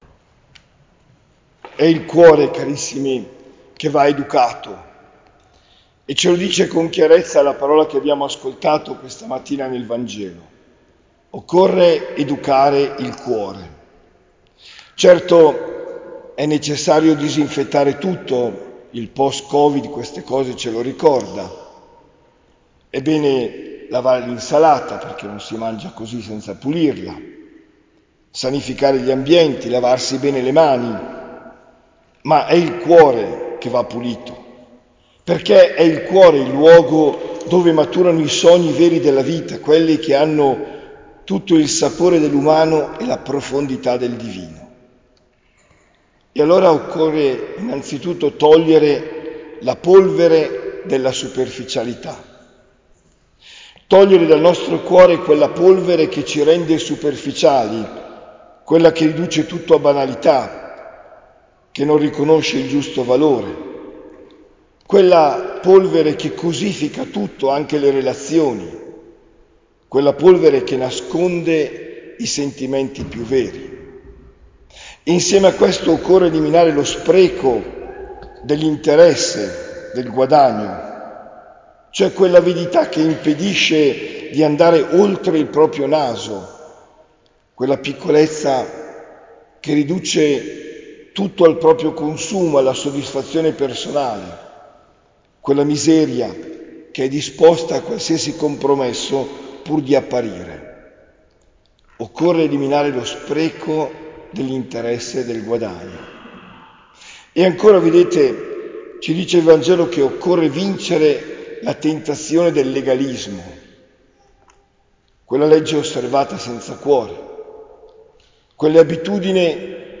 OMELIA DEL 1 SETTEMBRE 2024